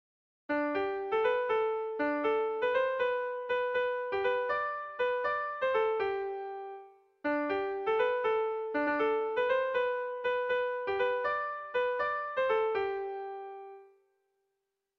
Haurrentzakoa
ABAB